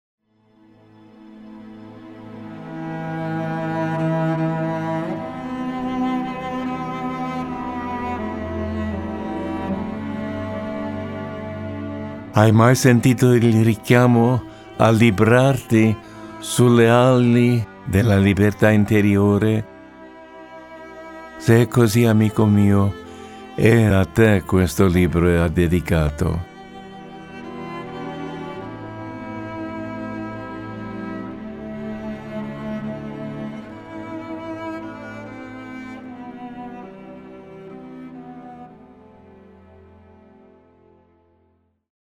La terra del sole d'oro - audiolibro scaricabile